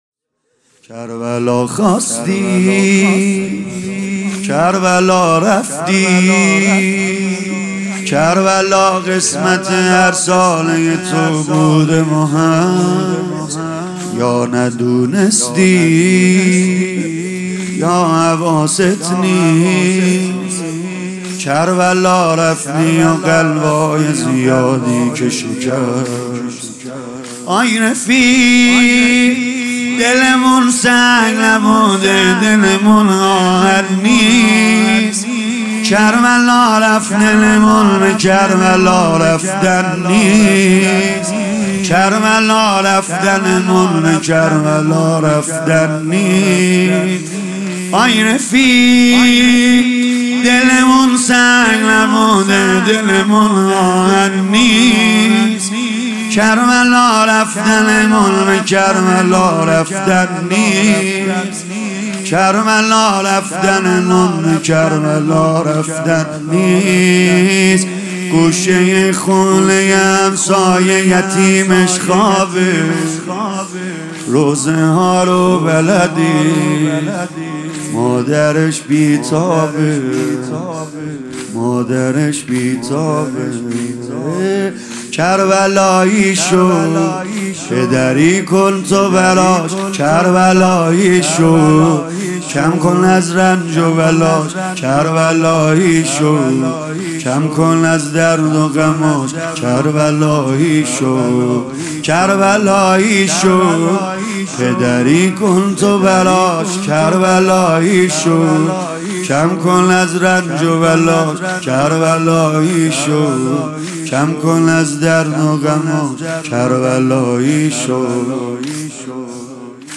زمینه | کربلا خواستی کربلا رفتی
شب دهم محرم ۹۹ - هیئت فدائیان حسین